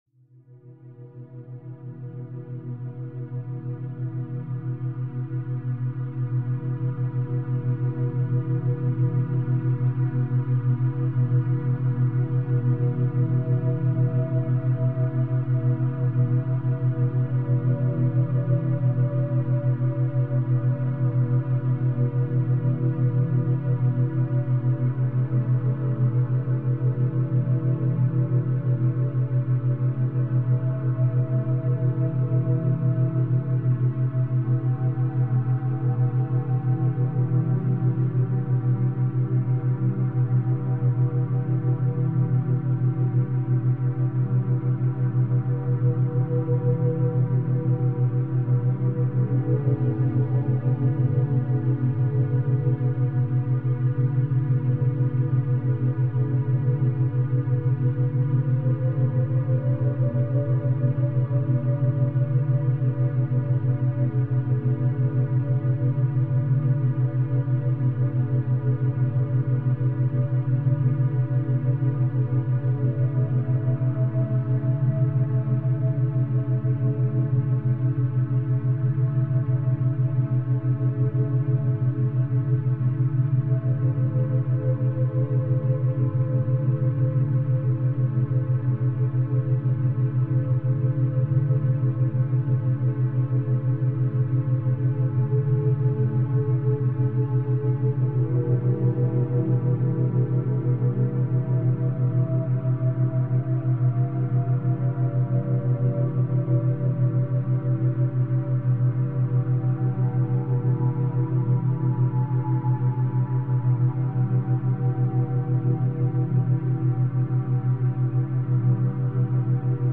深いリラクゼーション – 432 Hz + 7 Hz | 心 / 体のバランス | バイノーラルビート
勉強BGM